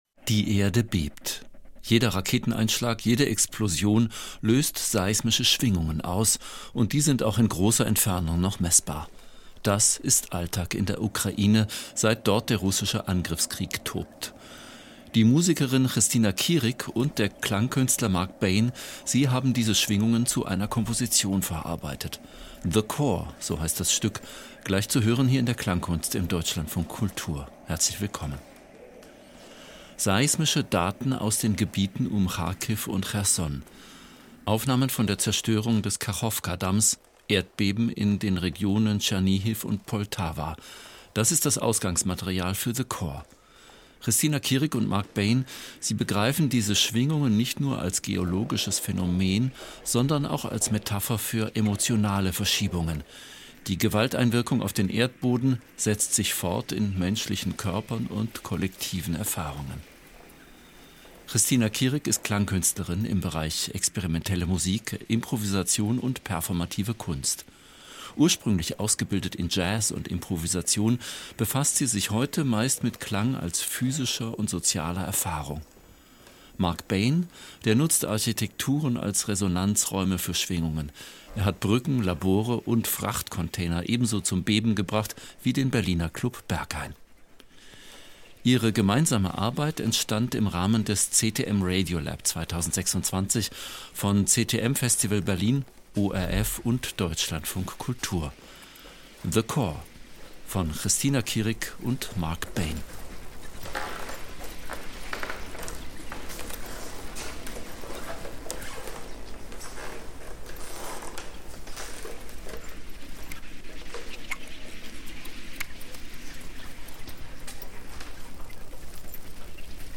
Hörspiele von Deutschlandfunk und Deutschlandfunk Kultur